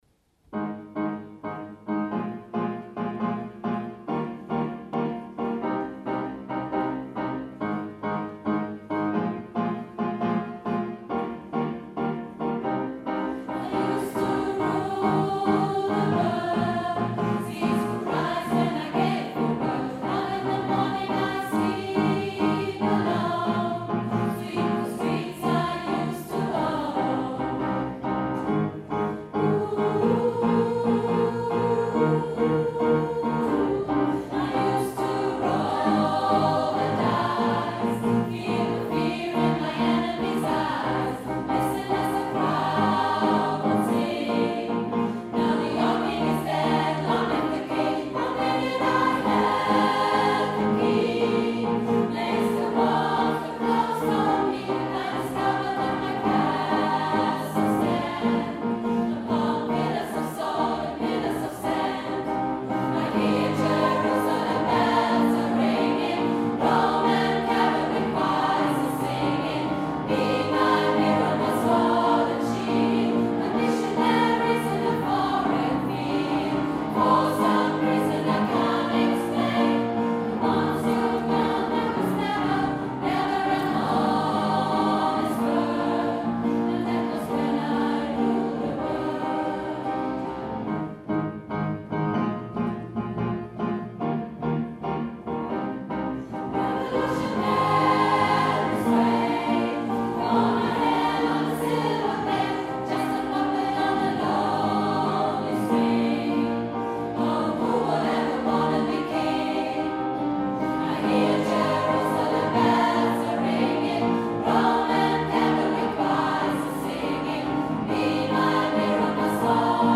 Hier einige Mitschnitte aus unserem gemeinsamen Konzert mit Resound im Stadttheater: